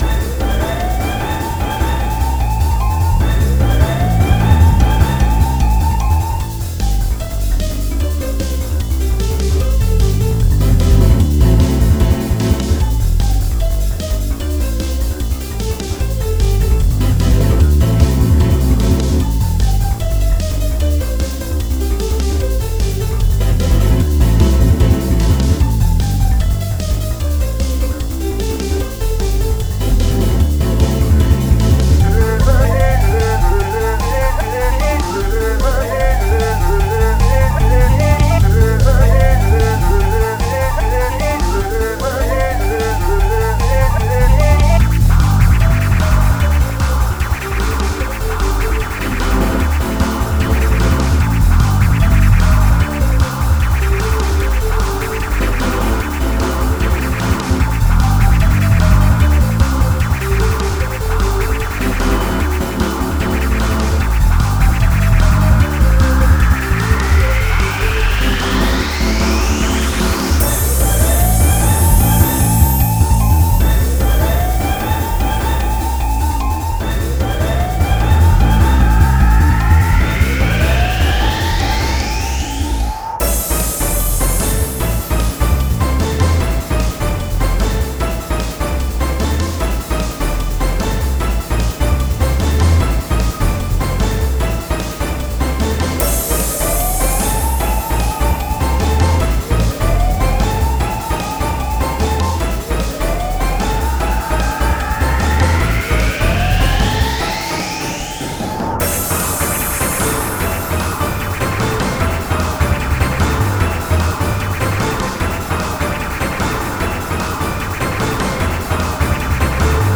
Heres the Inst/Vocals (BPM is 150, no bpm changes!)